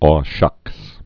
shŭks)